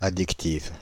Ääntäminen
Ääntäminen Paris: IPA: [a.dik.tiv] France (Île-de-France): IPA: /a.dik.tiv/ Haettu sana löytyi näillä lähdekielillä: ranska Käännöksiä ei löytynyt valitulle kohdekielelle.